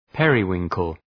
Προφορά
{‘perı,wıŋkəl}